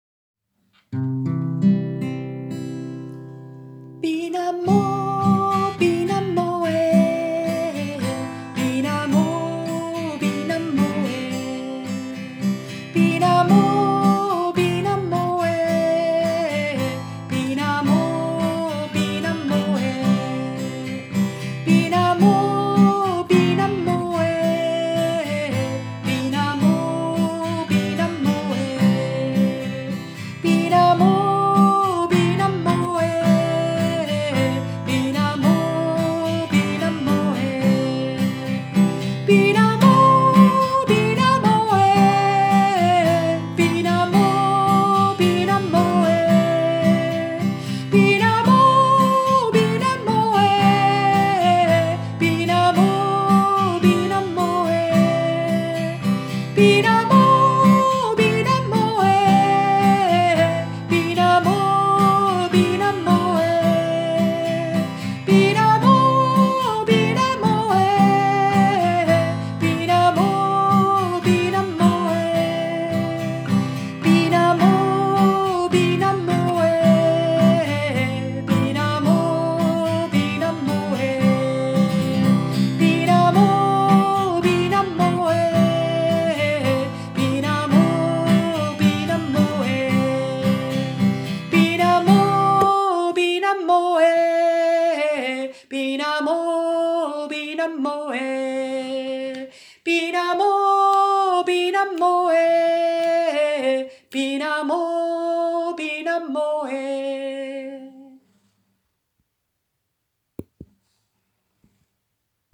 Binamo – Afrikanisches Willkommenslied